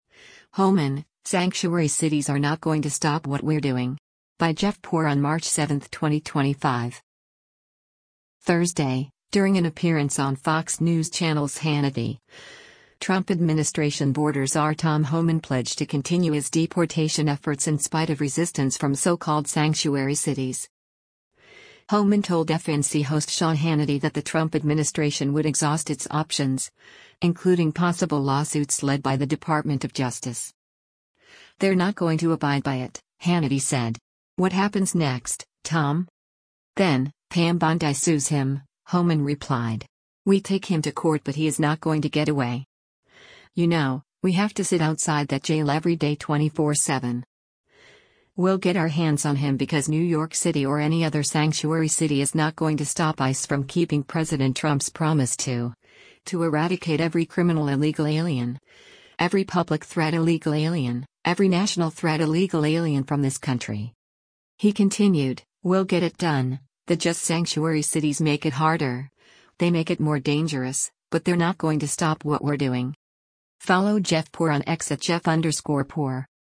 Thursday, during an appearance on Fox News Channel’s “Hannity,” Trump administration border czar Tom Homan pledged to continue his deportation efforts in spite of resistance from so-called sanctuary cities.
Homan told FNC host Sean Hannity that the Trump administration would exhaust its options, including possible lawsuits led by the Department of Justice.